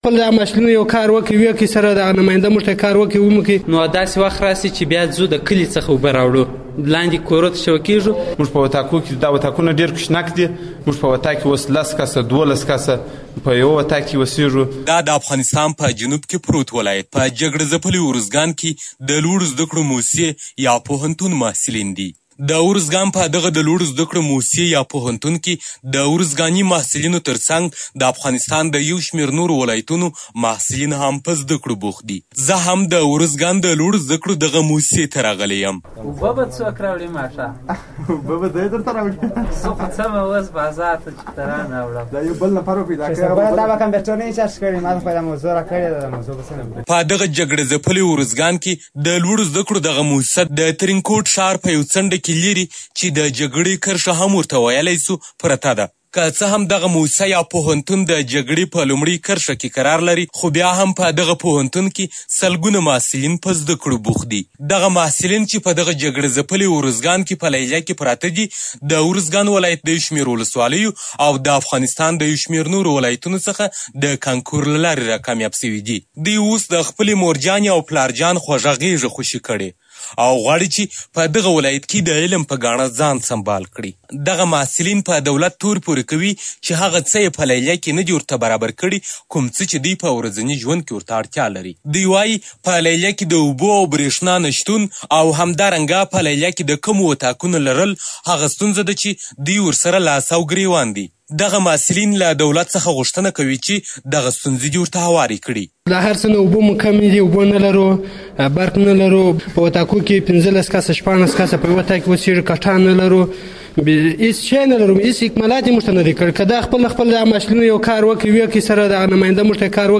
انځوریز راپور: